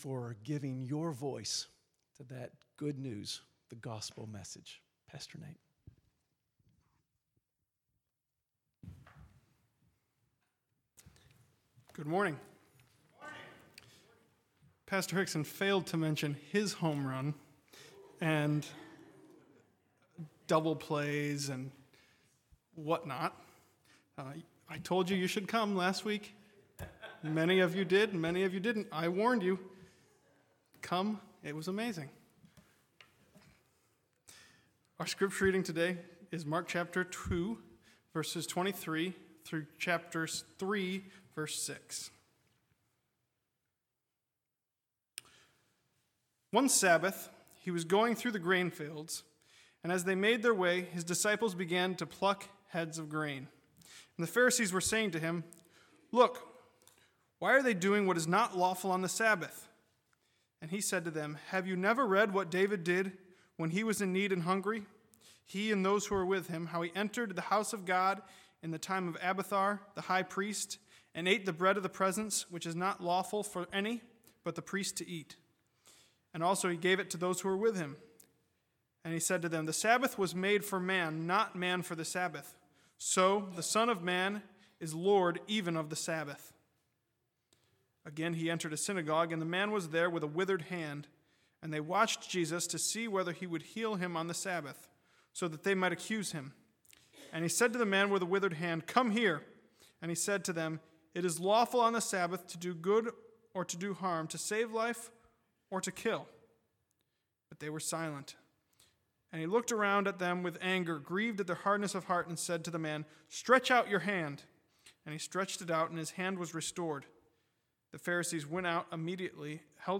Bible Text: Mark 2:23-3:6 | Preacher